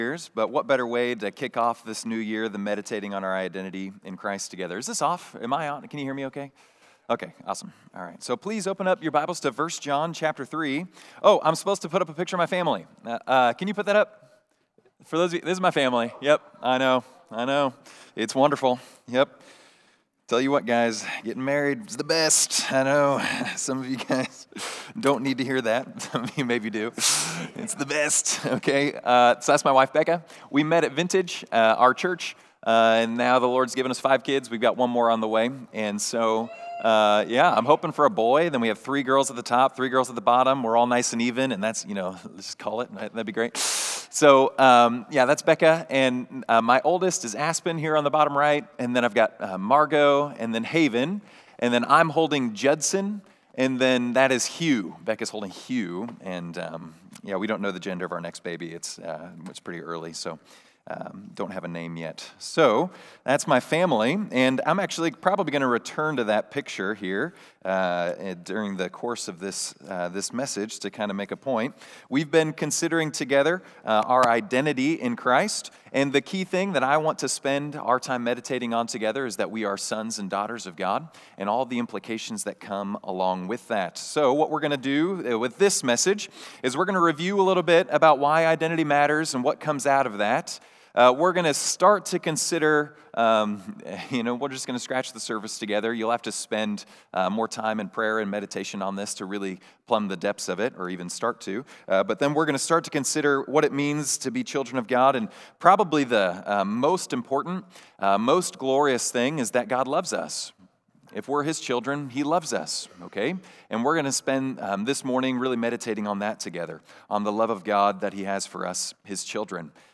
Sons of God | Winter Retreat 2025 | In Christ: Being before Doing - Campus Fellowship